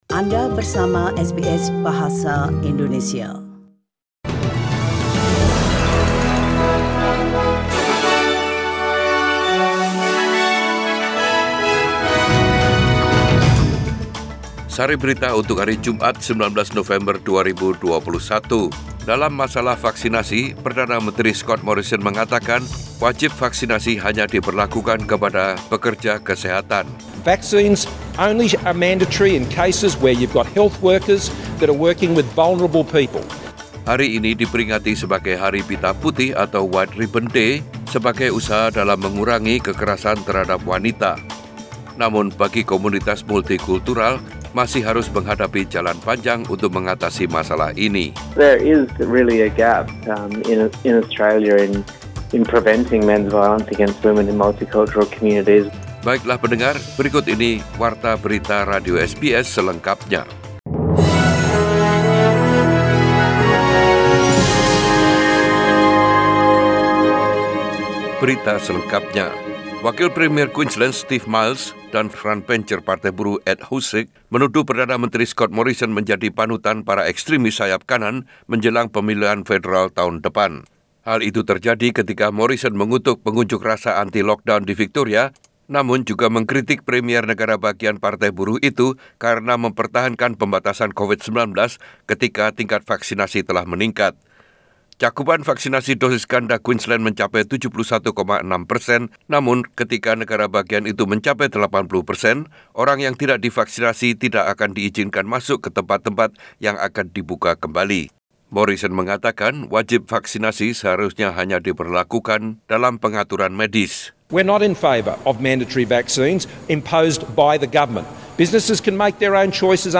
Warta Berita Radio SBS Program Bahasa Indonesia. Source: SBS